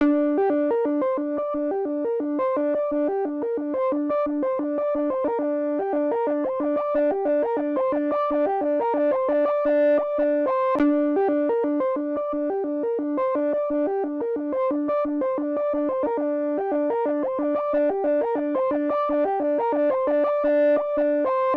03 lead B.wav